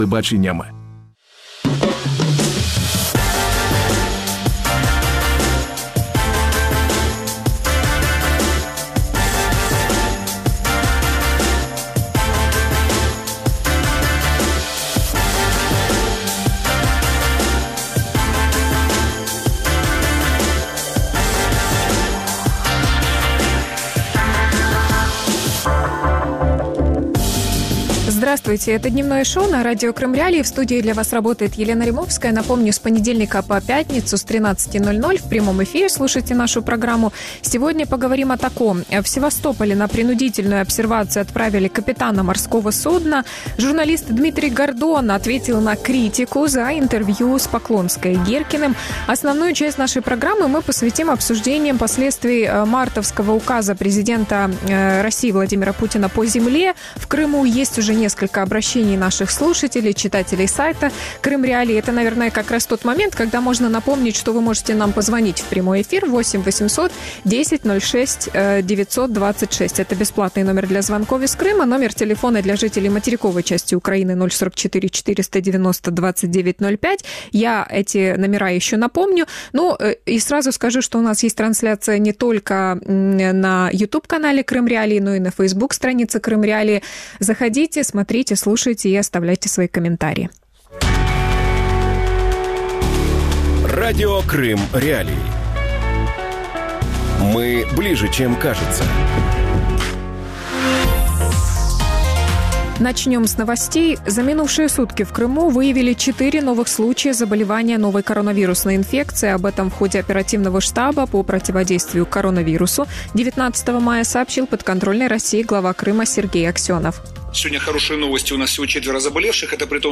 Крымская земля раздора. Чем грозит указ Путина | Дневное ток-шоу